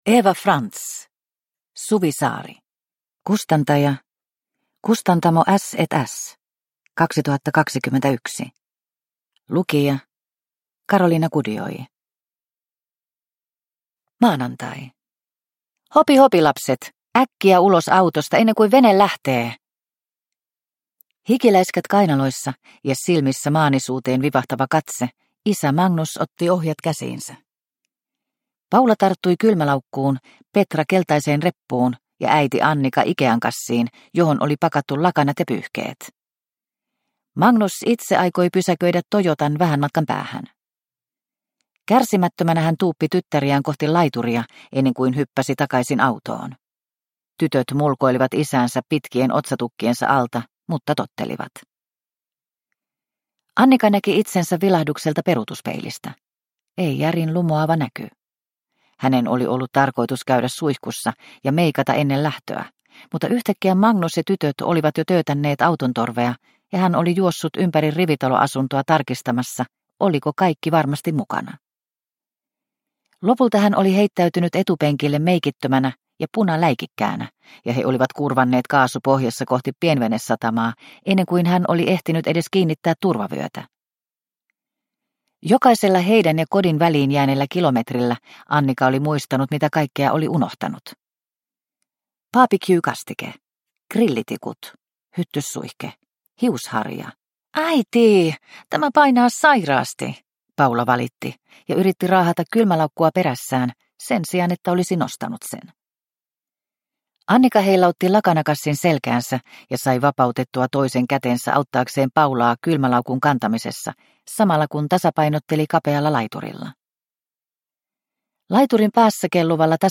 Suvisaari – Ljudbok – Laddas ner